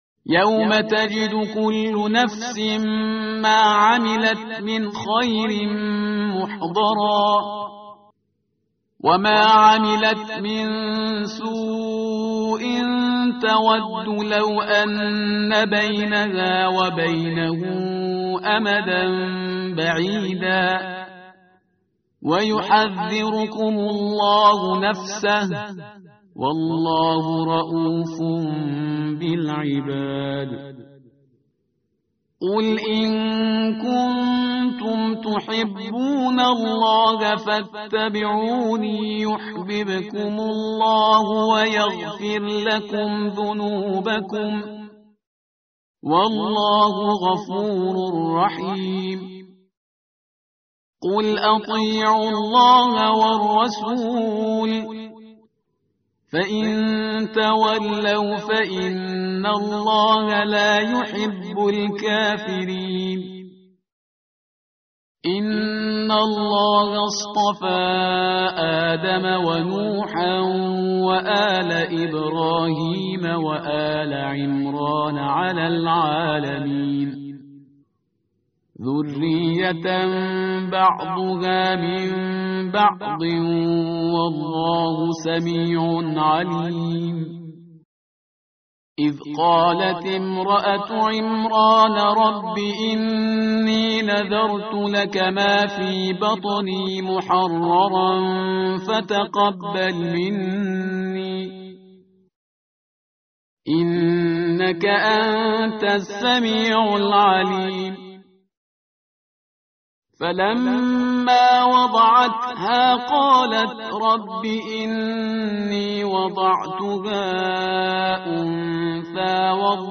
tartil_parhizgar_page_054.mp3